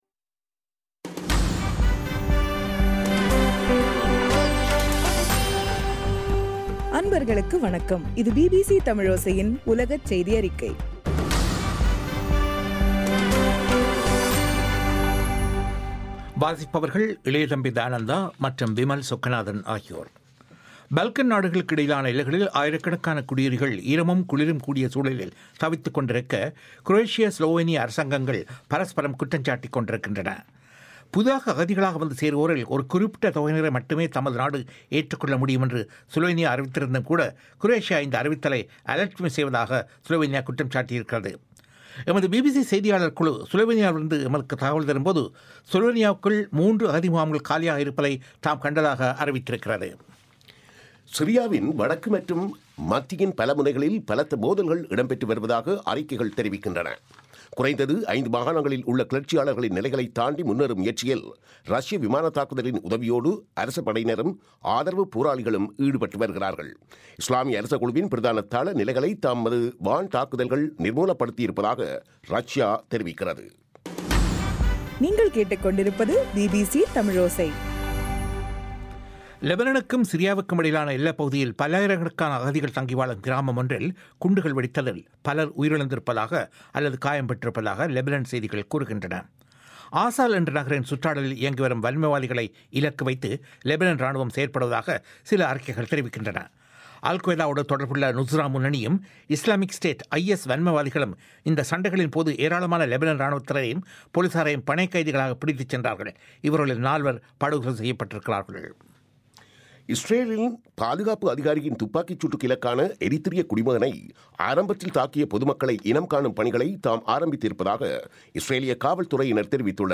இன்றைய (அக்டோபர் 19) பிபிசி தமிழ் செய்தியறிக்கை